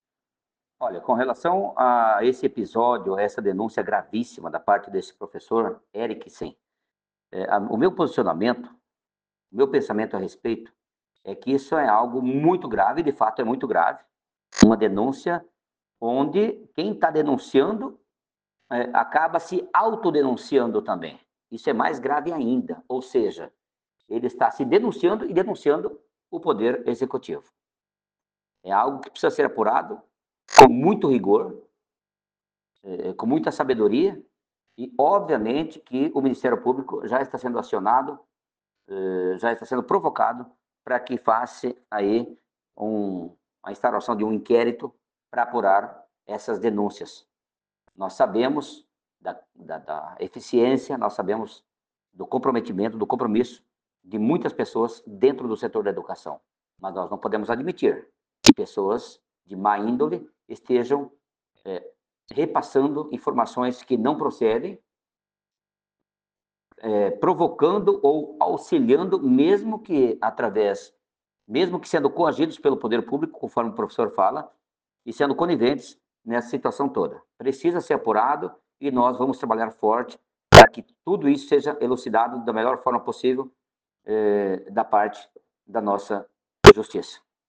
Em entrevista ao site Opinião MT, o vereador Hélio Kaminski disse que a denúncia é grave.
OUÇA O ÁUDIO DA ENTREVISTA CONCEDIDA PELO VEREADOR HELIO KAMINSKI